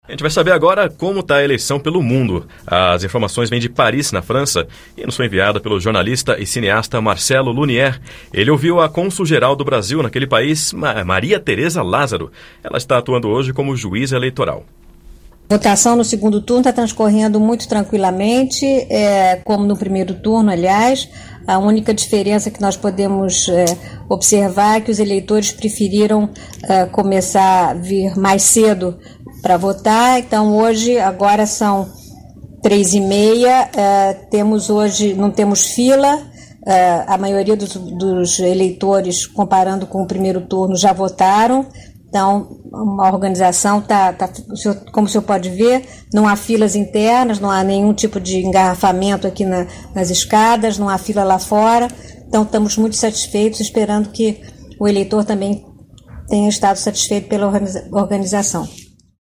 A informação é da consul geral do Brasil naquele país, Maria Teresa Lázaro.
A entrevista